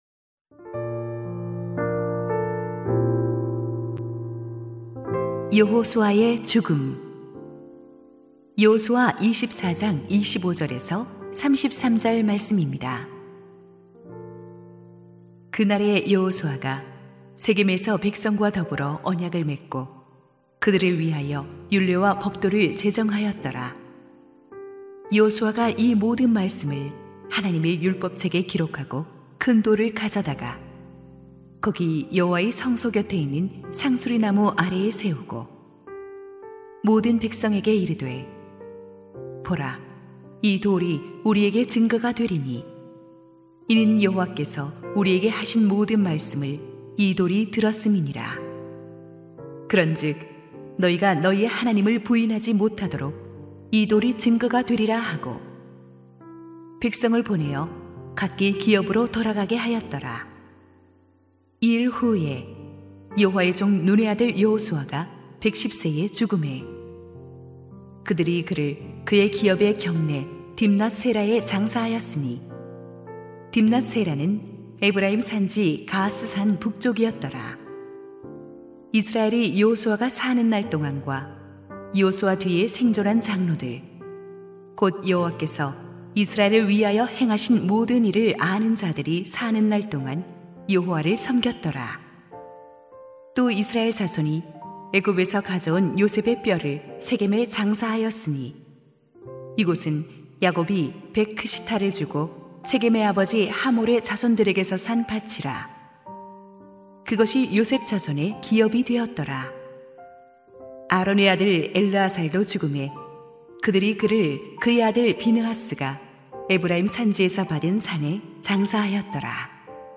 [새벽예배]
새벽기도회